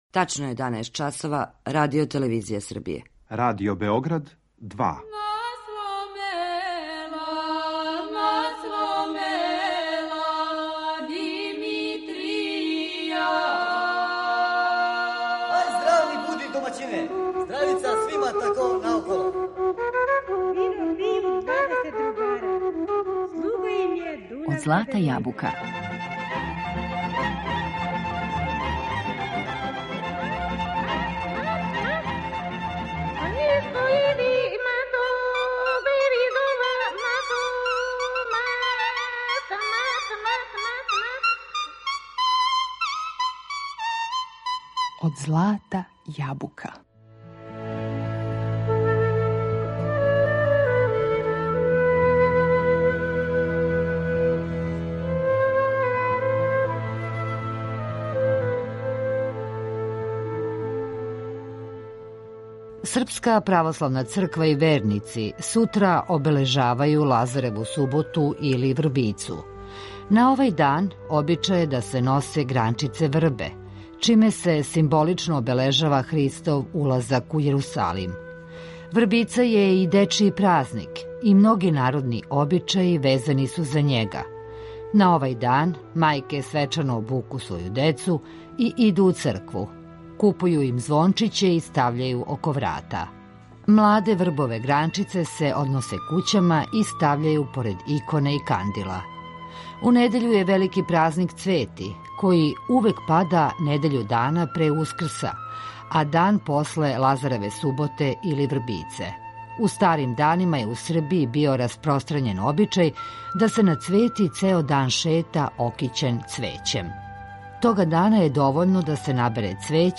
У данашњој емисији Од злата јабука говорићемо о народним обичајима, веровањима, легендама и предањима везаним за ова два значајна датума у српском обичајном календару, а причу ћемо употпунити добром традиционалном песмом и игром.